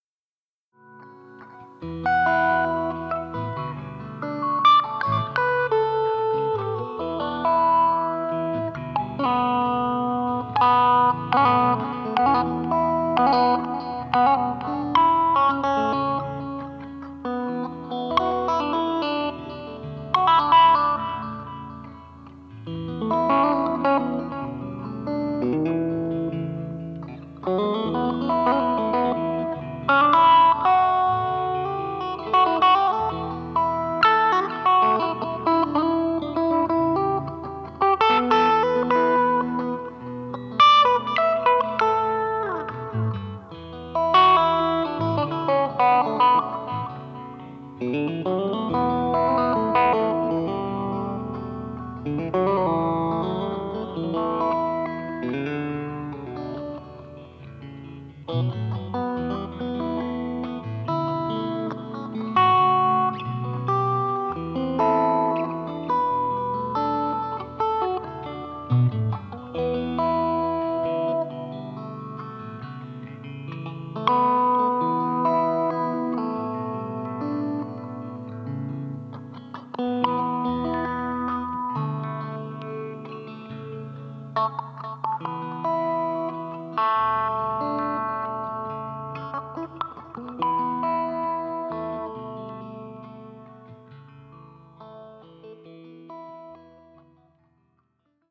Wieder zuhause, fing es an zu regnen, und ich fand den Mut, mein musikalisches Equipment anzuschliessen.
Sehr schöne reine Klänge.